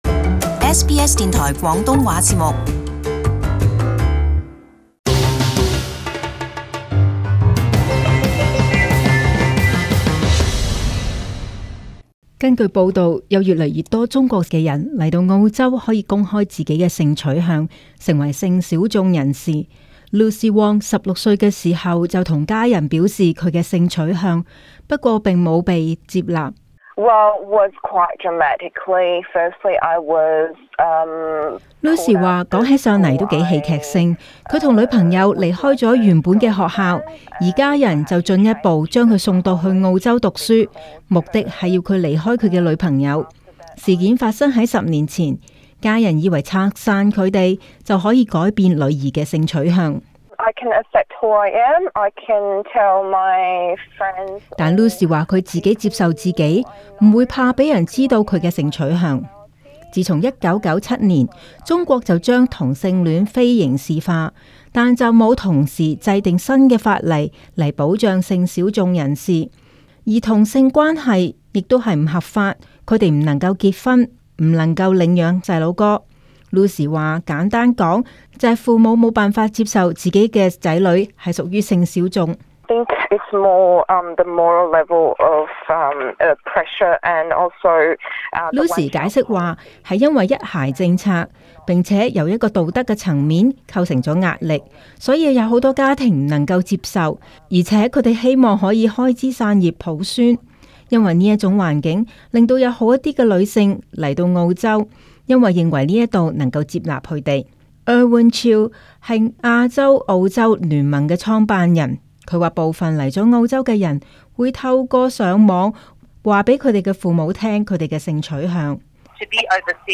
【时事报导】出柜在澳洲